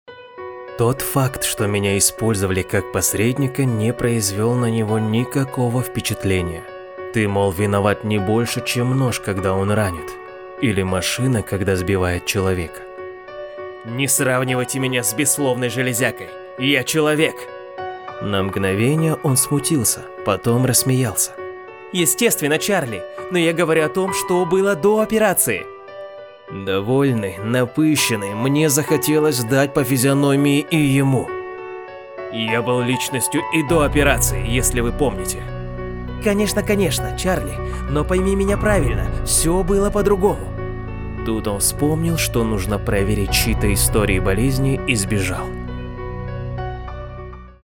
Аудиокнига
Муж, Аудиокнига
Запись производится в студии, оборудование (Звук.карта, Микрофон, наушники- набор марки «Steinberg»).